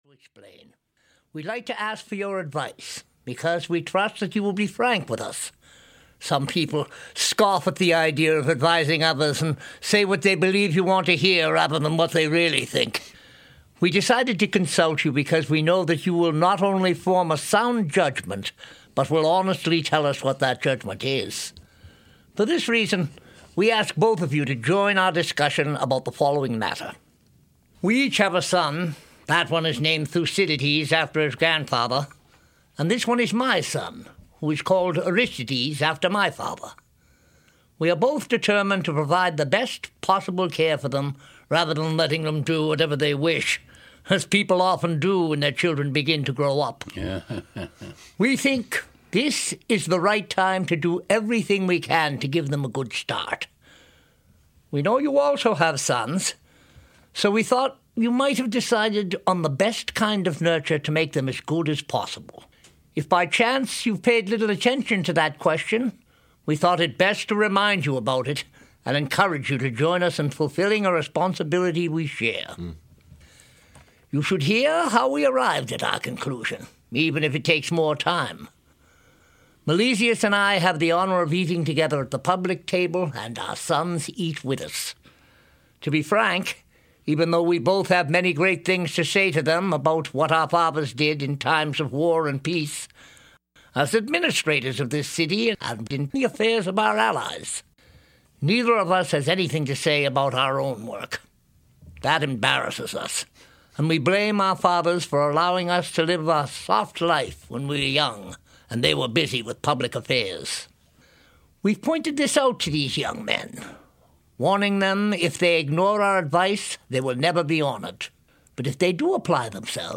Audio knihaPlato’s Laches (EN)
Ukázka z knihy